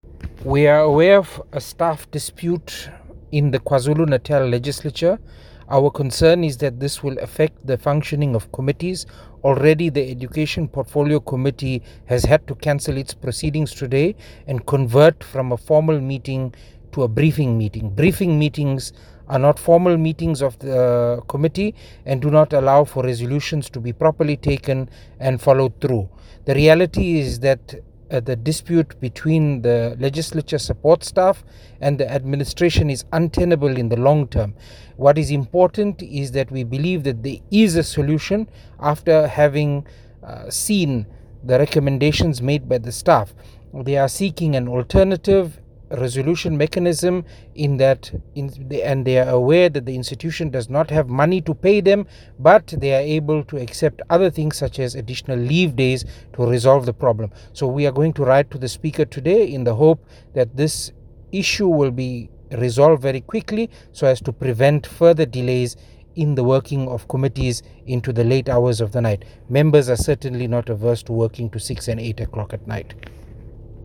Note to the Editors: Please note Dr Imran Keeka, MPL sound bite in